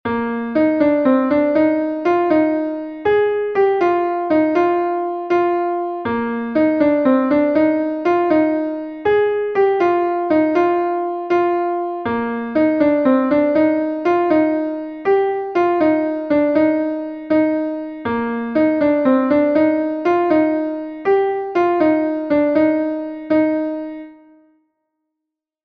Gavotenn Plourac'h est un Gavotte de Bretagne